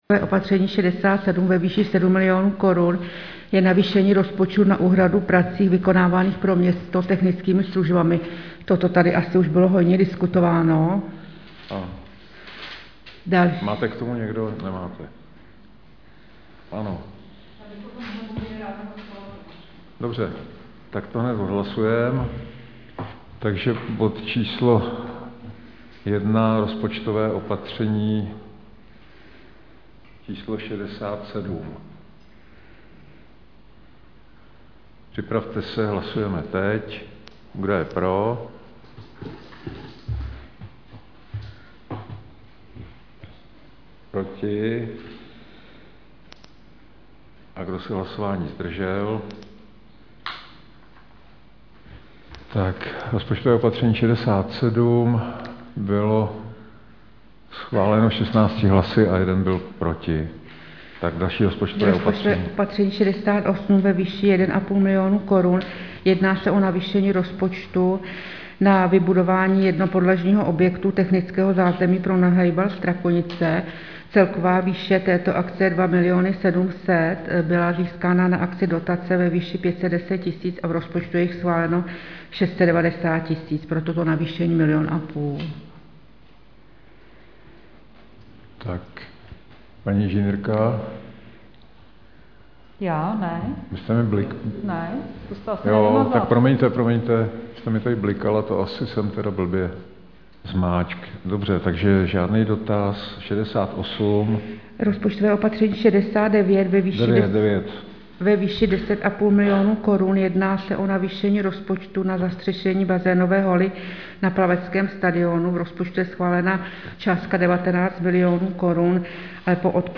Záznam jednání: